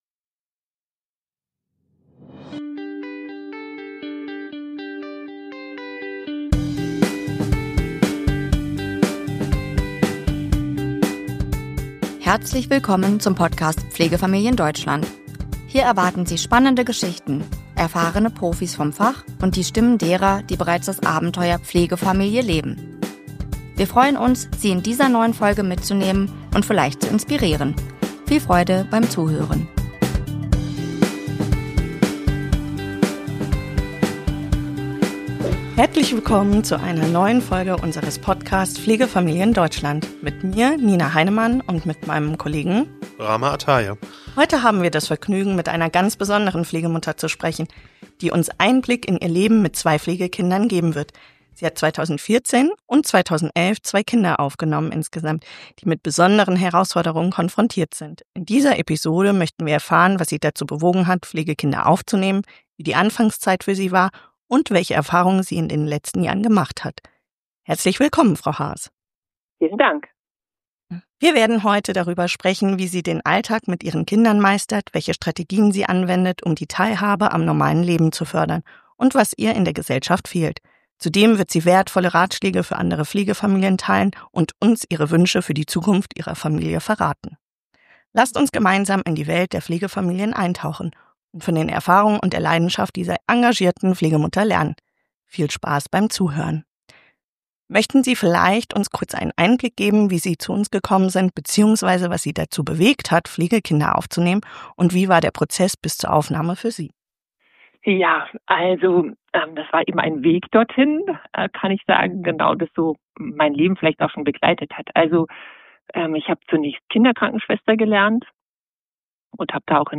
Eine Pflegemutter erzählt von ihrem besonderen Alltag ~ Pflegefamilien Deutschland Podcast